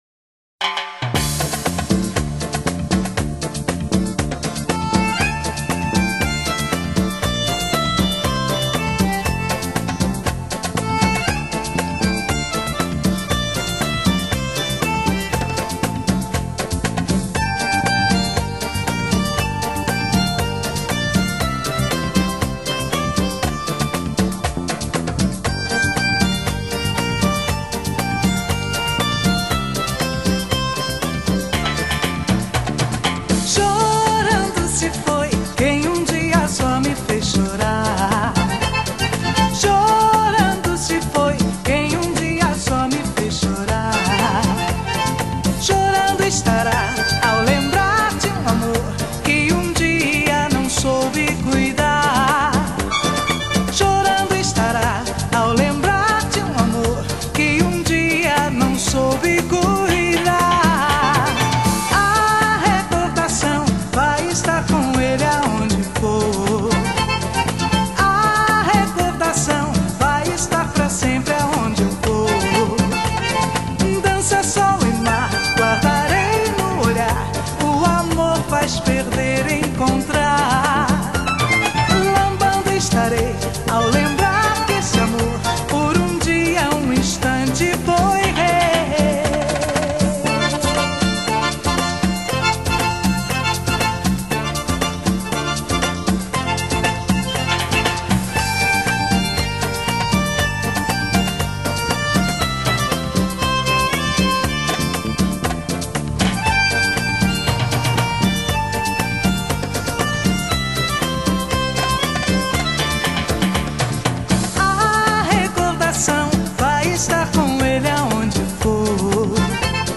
Pop,Latin,Dance